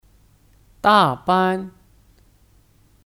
大班 (Dàbān 大班)